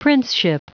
Prononciation du mot princeship en anglais (fichier audio)
Prononciation du mot : princeship